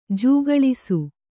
♪ jūgaḷisu